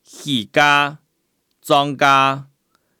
Hakka tts 用中文字典方式去mapping客語語音 客語語音來源 1.